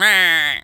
duck_quack_hurt_01.wav